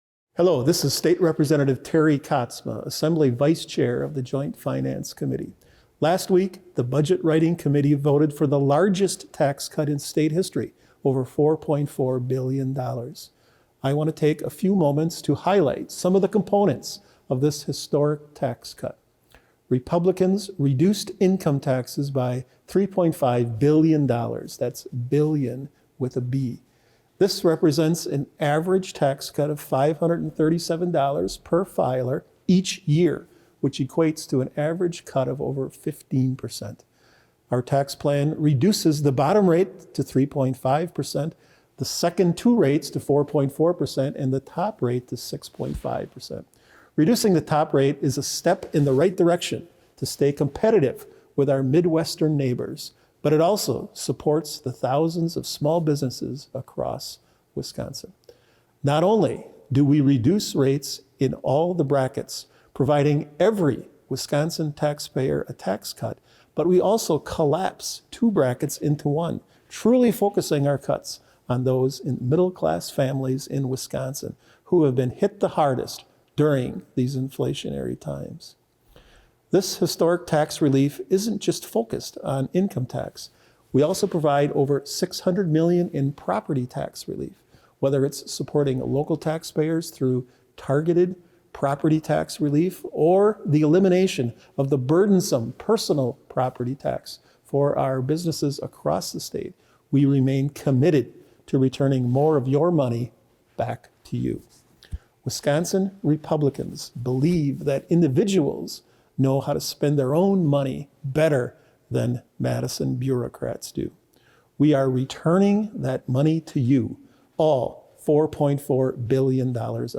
Weekly GOP radio address: Rep. Katsma on historic tax cuts - WisPolitics
MADISON – Representative Katsma (R – Oostburg) released the weekly radio address on behalf of Wisconsin Legislative Republicans.